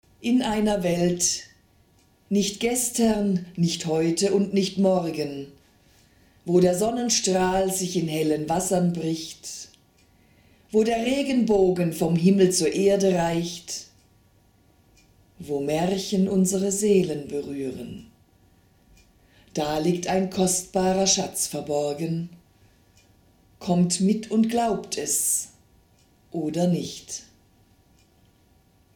Märchenerzählerin
Schließen Sie die Augen, lauschen Sie der Magie meiner Stimme und lassen Sie sich von dieser besonderen Geschichte in eine zauberhafte Welt entführen.